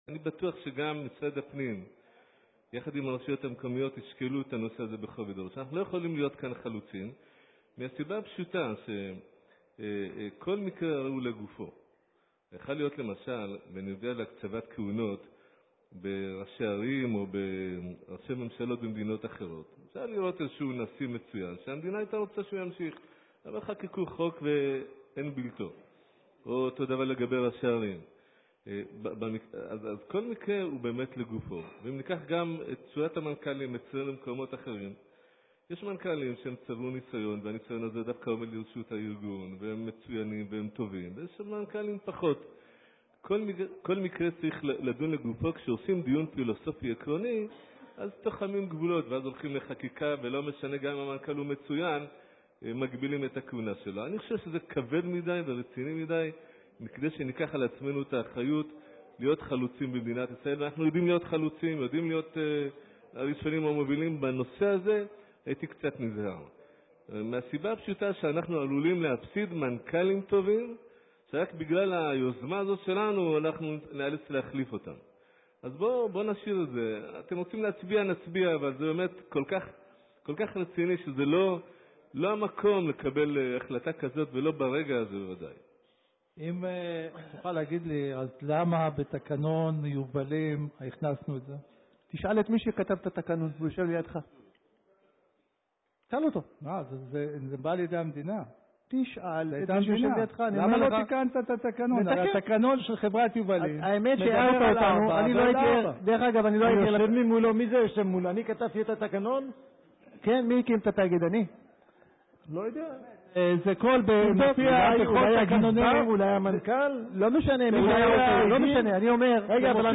ישיבות מועצה
11-3-15-2-מועצה רגילה.mp3